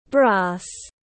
Đồng thau tiếng anh gọi là brass, phiên âm tiếng anh đọc là /bræs/.